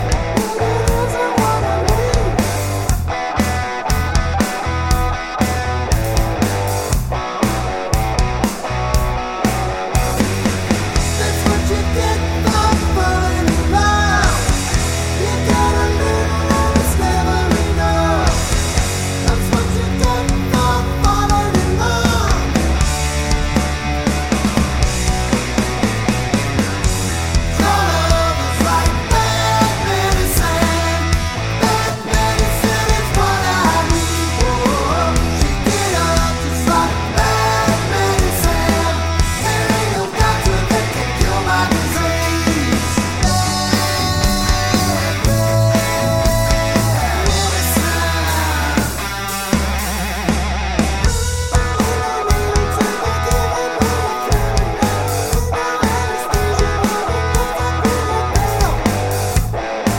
no Backing Vocals Rock 5:19 Buy £1.50